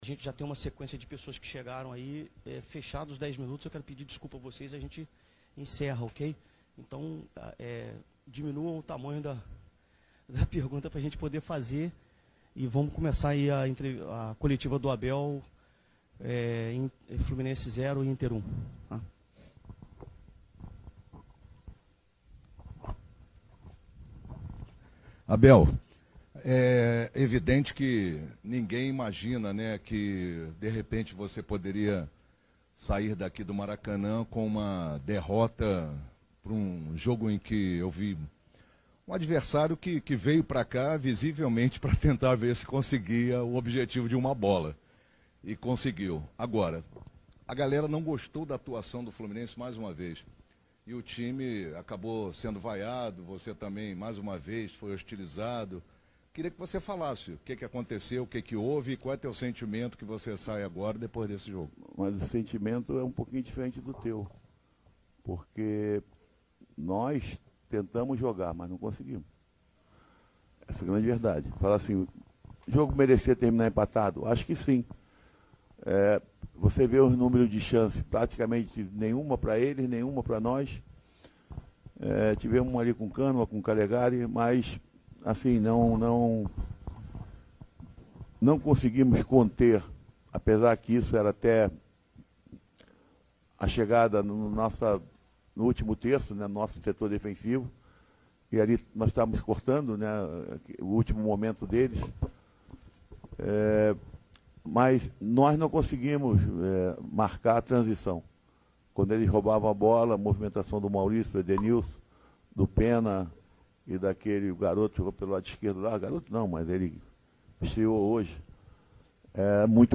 Na entrevista coletiva após o jogo contra o Internacional no último sábado, eu perguntei ao treinador Abel Braga se teria tido por parte da diretoria tricolor alguma determinação sobre prioridade nas competições:
Ouça aqui a coletiva do Abel Braga